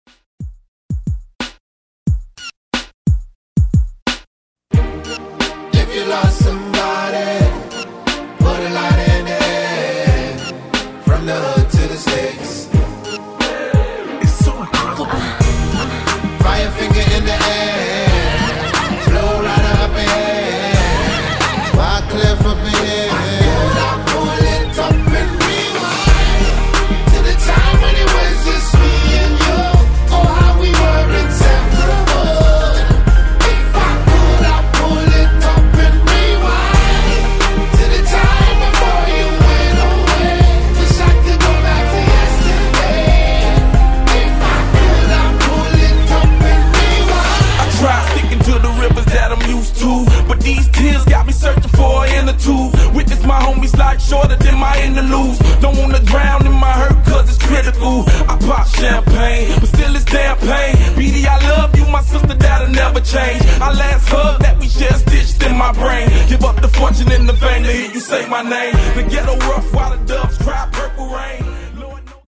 Genre: 90's
Clean BPM: 108 Time